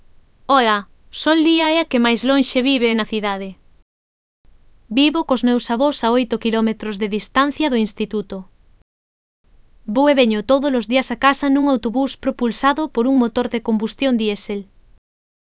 Elaboración propia (proxecto cREAgal) con apoio de IA, voz sintética xerada co modelo Celtia.